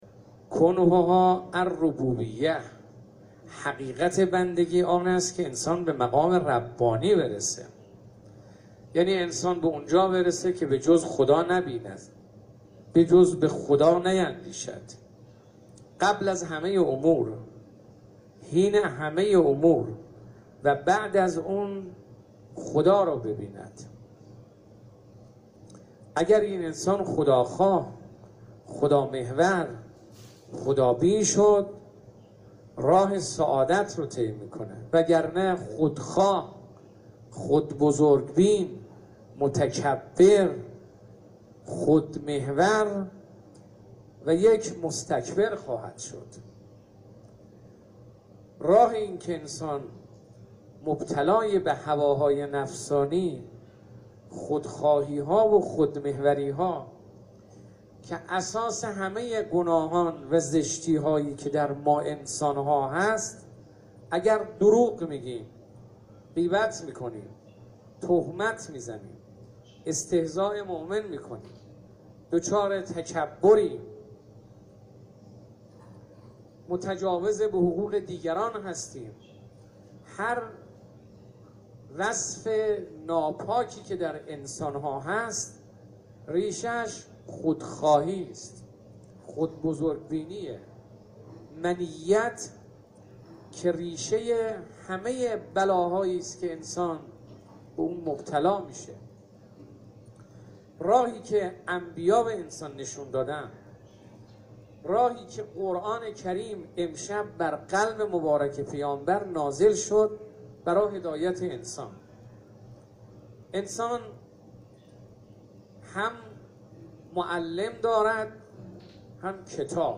به گزارش خبرنگار خبرگزاری رسا، آیت الله ابراهیم رئیسی رییس قوه قضاییه، شب گذشته در آستان مقدس امامزاده صالح(ع) به ایراد سخنرانی پرداخت و گفت: همه عالم از آن پروردگار است.